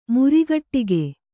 ♪ murigaṭṭige